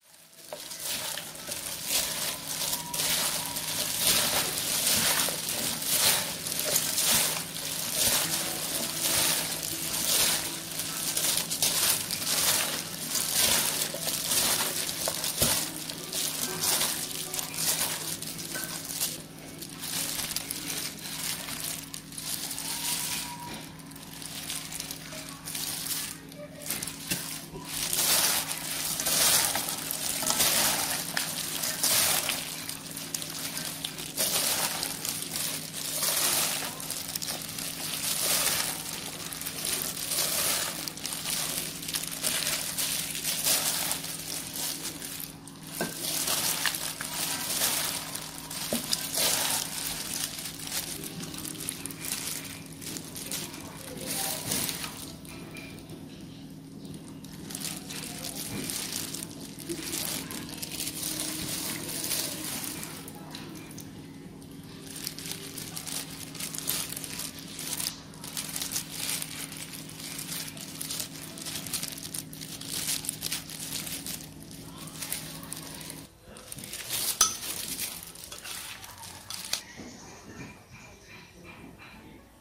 Tiếng Trộn gỏi, Trộn đồ ăn bằng bao tay ni lông
Thể loại: Tiếng ăn uống
Description: Tiếng Trộn gỏi, Trộn đồ ăn bằng bao tay ni lông sột soạt, rọc rẹc rất an toàn vệ sinh thực phẩm...
tieng-tron-goi-tron-do-an-bang-bao-tay-ni-long-www_tiengdong_com.mp3